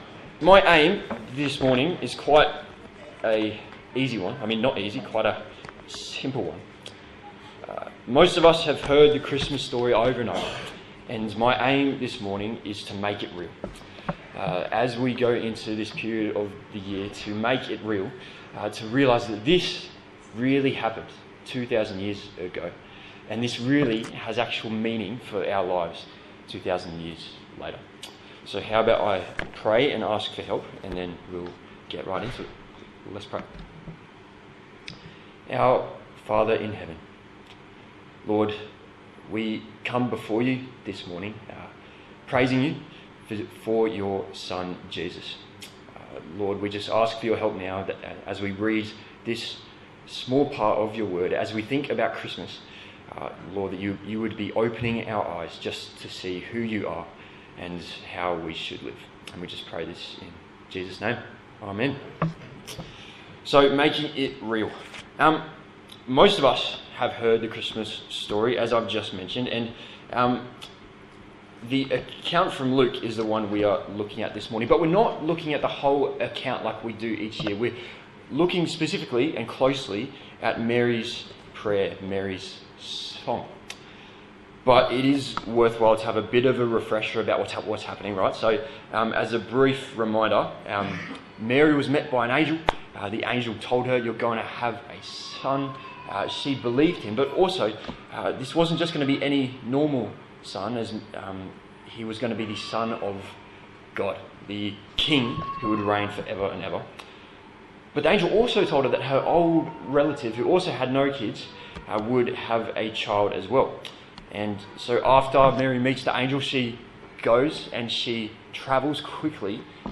Service Type: Sunday Morning A sermon on Mary's Prayer from the Gospel of Luke